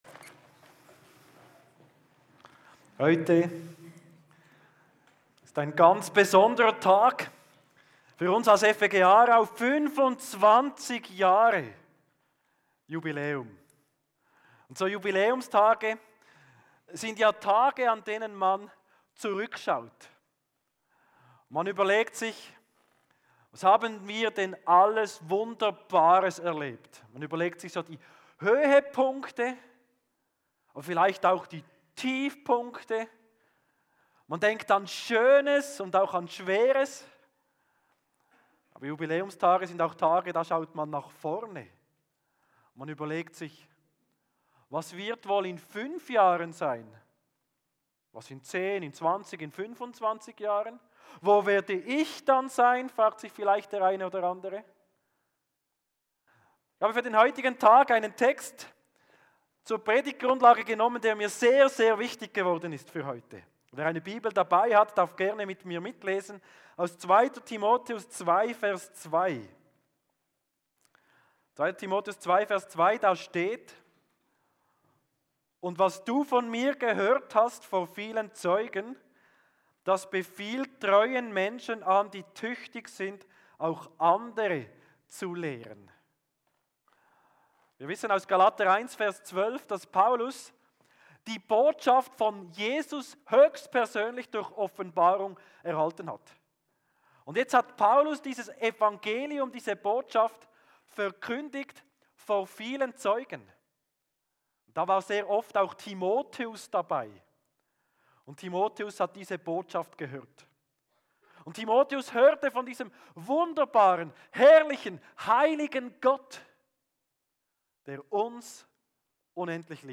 Kategorie: Predigt Tags: 2.